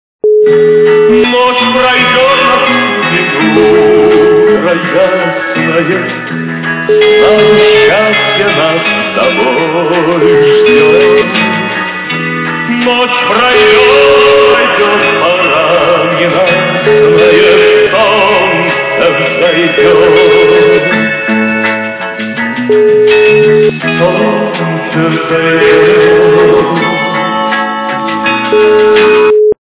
- из мультфильмов
При заказе вы получаете реалтон без искажений.